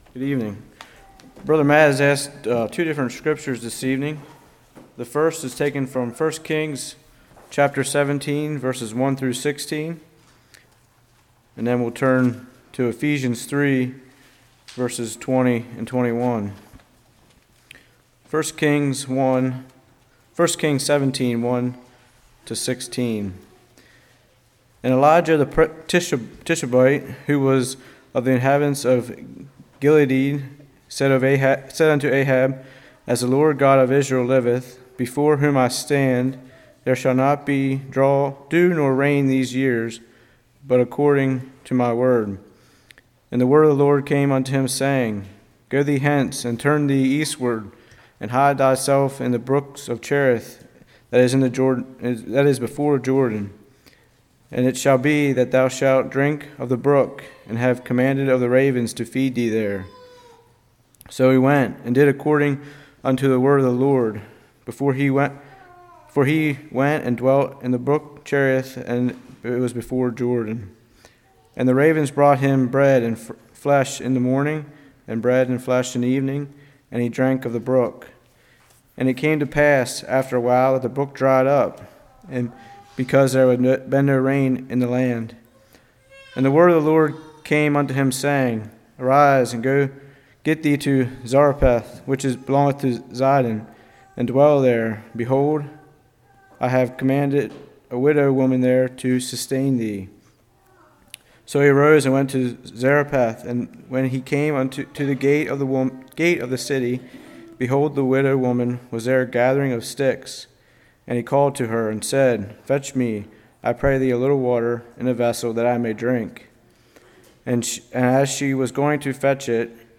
Ephesians 3:20-21 Service Type: Evening Remember what God has done.